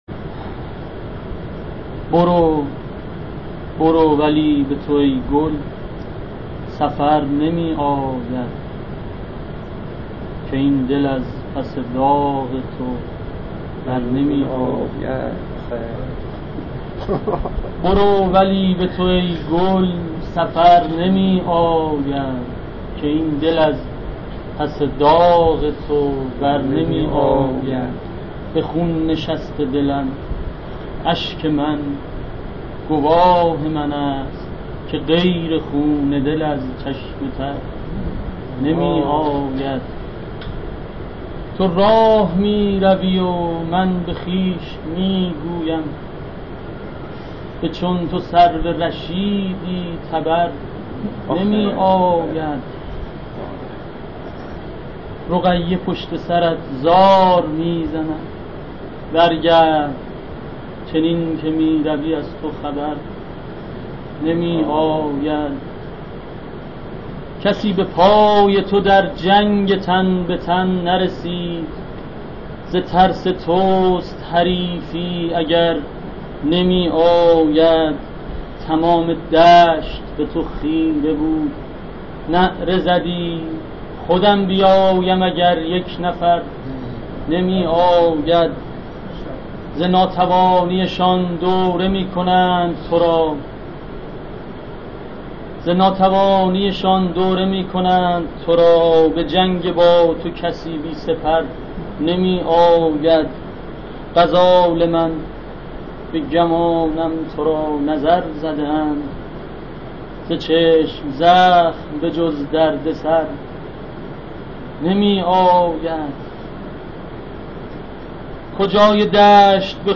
در ادامه متن اشعار و صوت شعر خوانی شعرا را مشاهده می کنید: